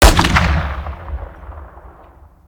weap_br2_fire_plr_atmo_ext1_05.ogg